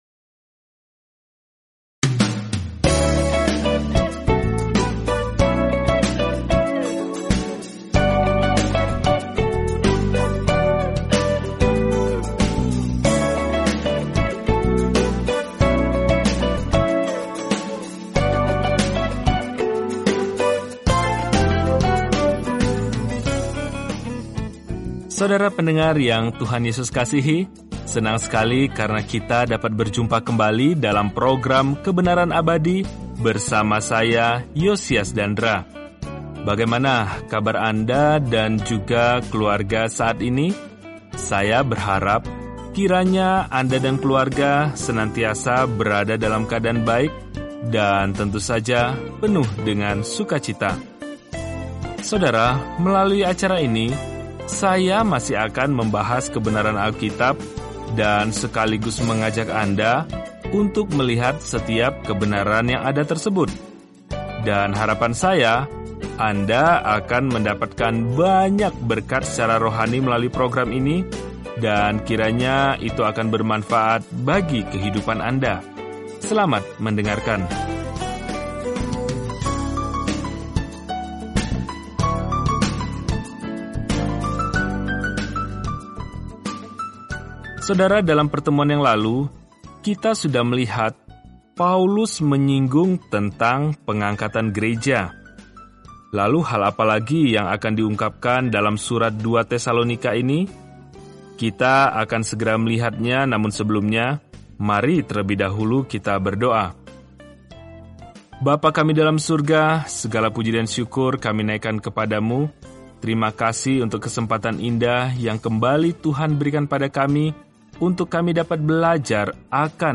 Telusuri 2 Tesalonika setiap hari sambil mendengarkan pelajaran audio dan membaca ayat-ayat pilihan dari firman Tuhan.